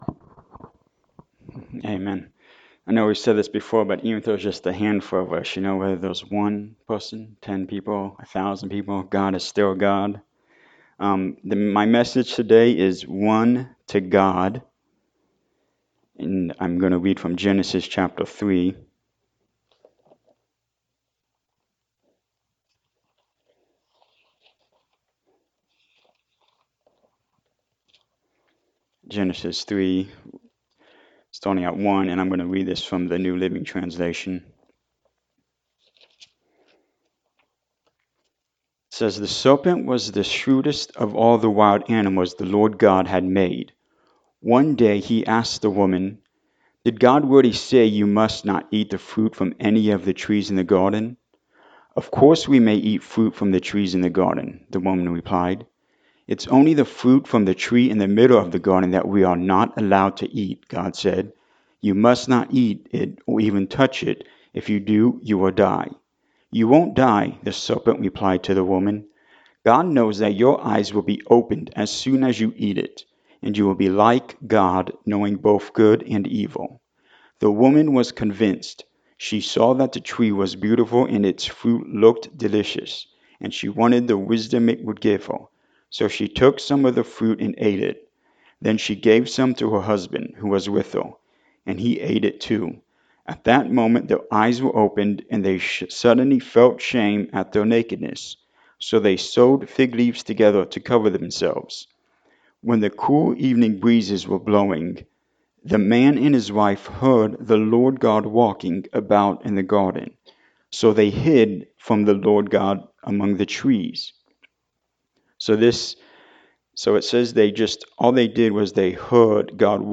Genesis 3:1 Service Type: Sunday Morning Service When you mess up
Sunday-Sermon-for-June-9-2024.mp3